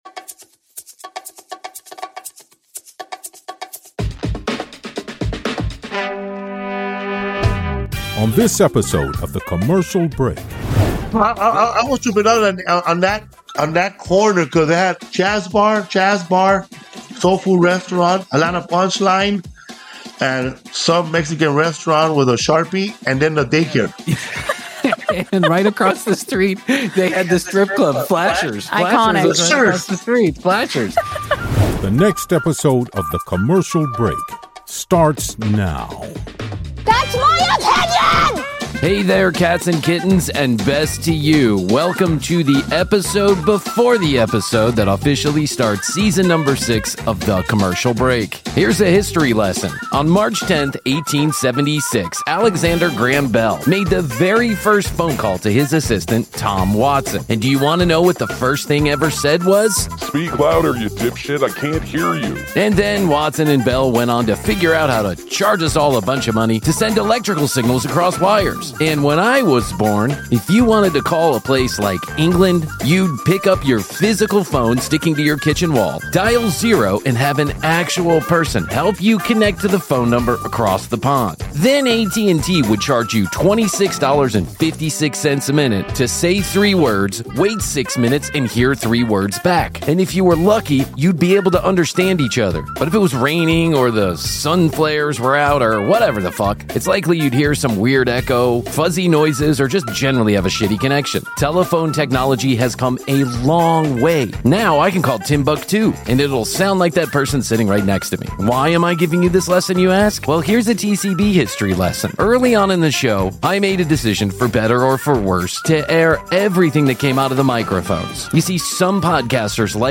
sat down with comedian Felipe Esparza before the holidays to chat all things food, fun and Atlanta Jazz Clubs!